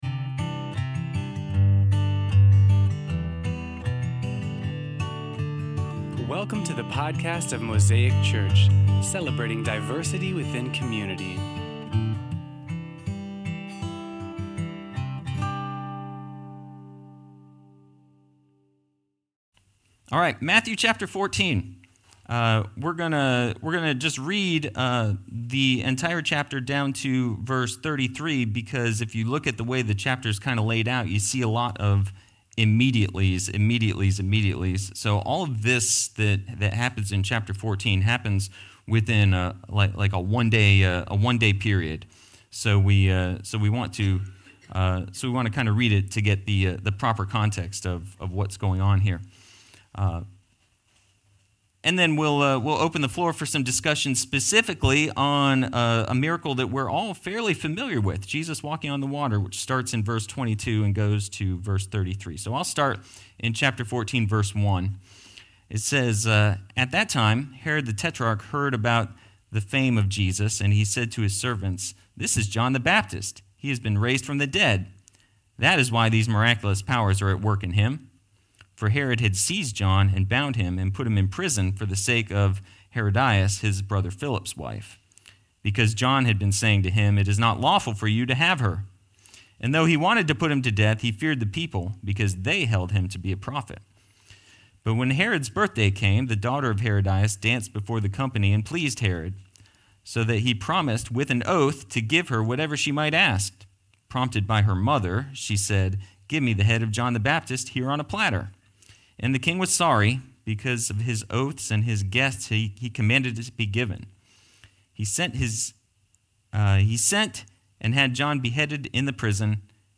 Sermon Series on Matthew's Gospel